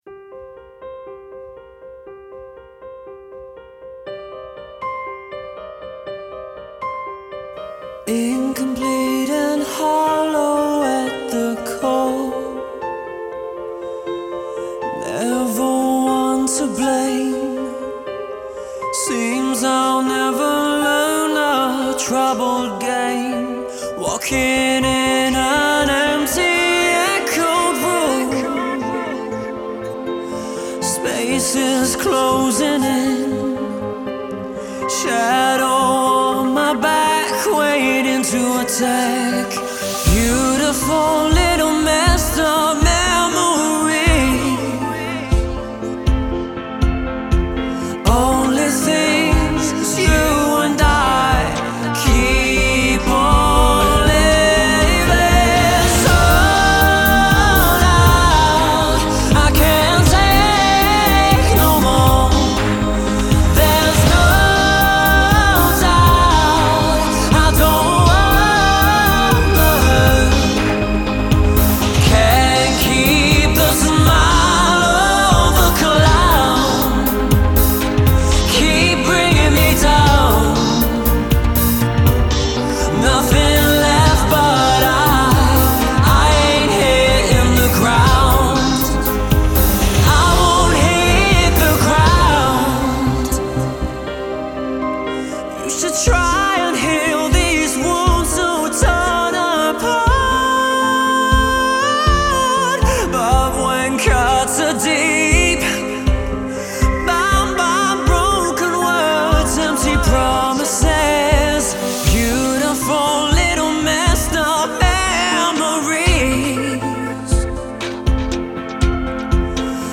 Всё чаще играю на бас гитаре и записываю несложные партии в процессе аранжировки. В песне могут быть и барабанные петли, и записанные аудио дорожки барабанов, и семплер типа Superior 3, особенно в начале процесса, когда пробуешь разные варианты для разных частей.
И отрывок аранжировки (промежуточный вариант на данный момент) без LPF